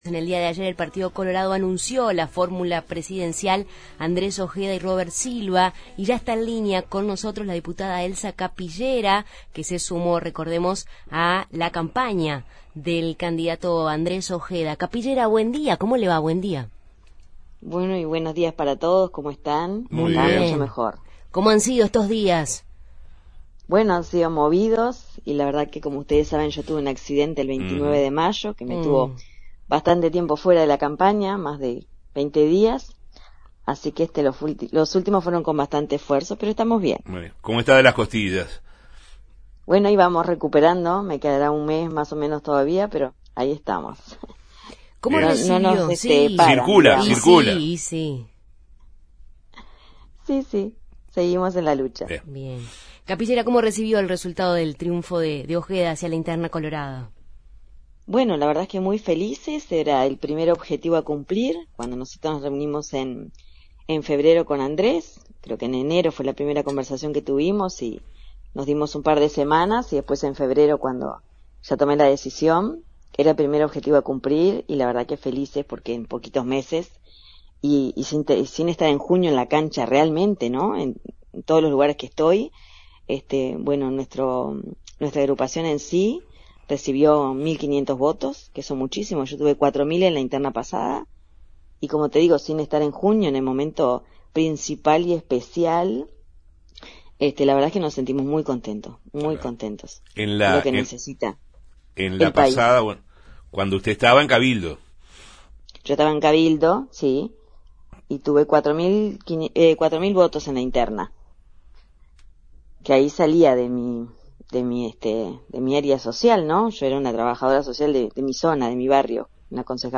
En Justos y pecadores entrevistamos a la diputada Elsa Capillera, que abandonó Cabildo Abierto para sumarse a la campaña del ahora candidato del Partido Colorado, Andrés Ojeda